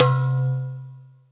AFFRBELLC2-L.wav